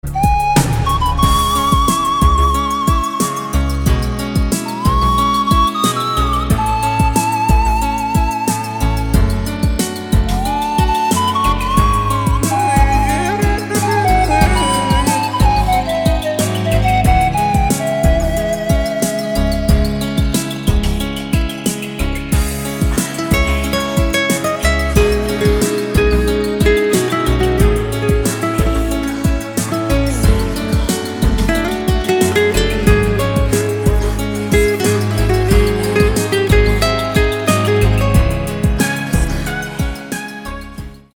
• Качество: 192, Stereo
гитара
красивые
спокойные
без слов
инструментальные
пианино
романтичные
Флейта